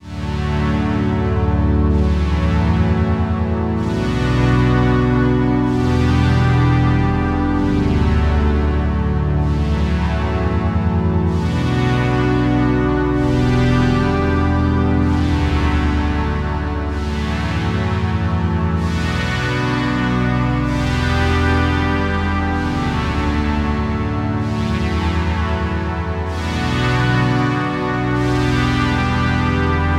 Дано: аудиофайл пэда с низкими и высокими частотами.